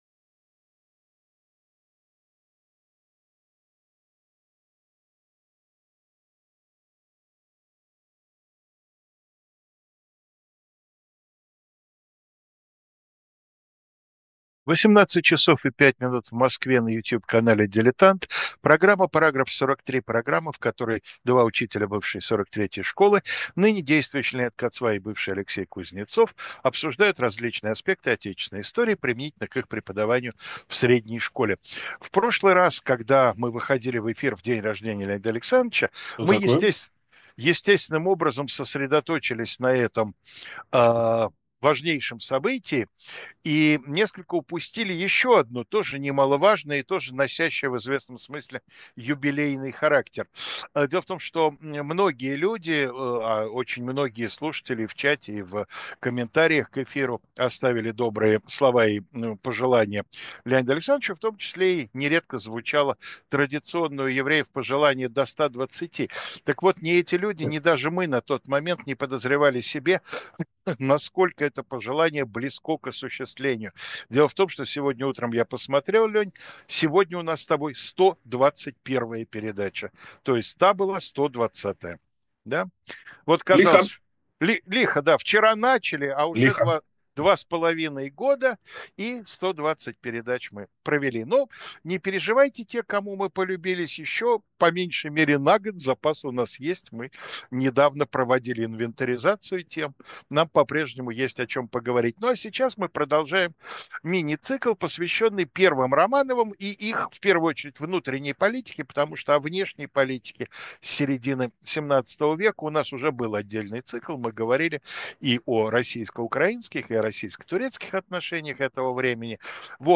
Эфир